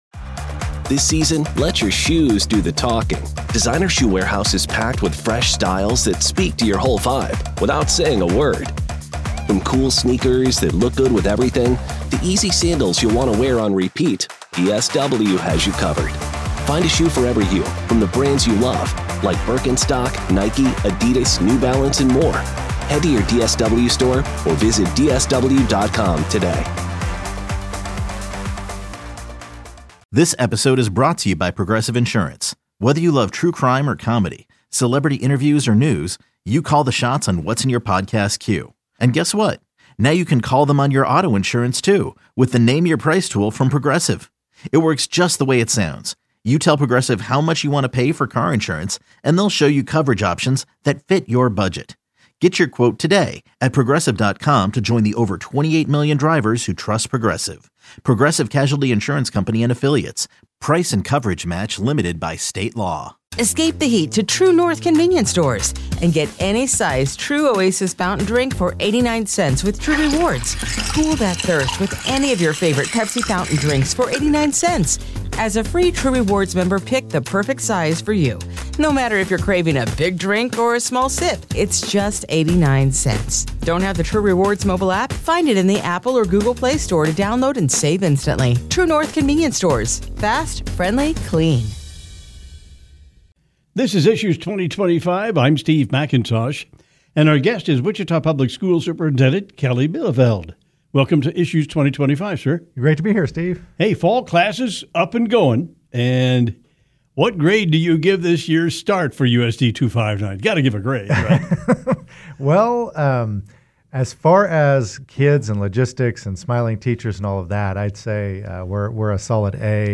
Listen to in-depth discussions with local leaders about issues in the Wichita community.